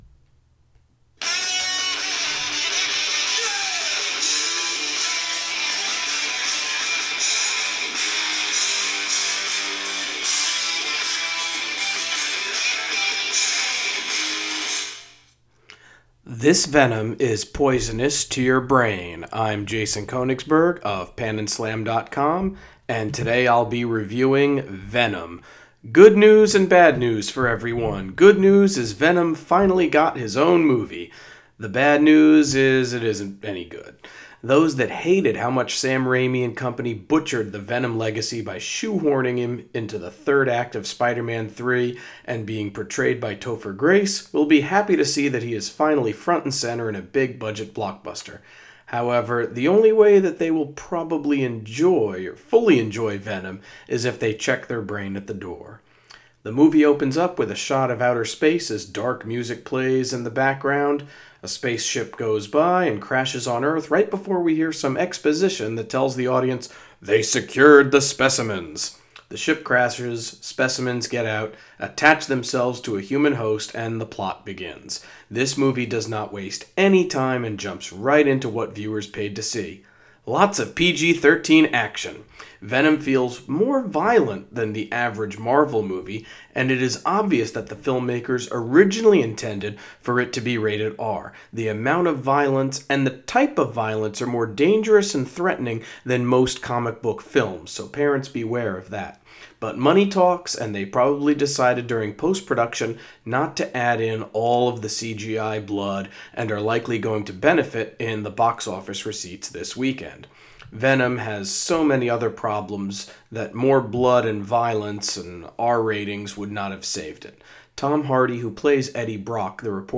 Movie Review: Venom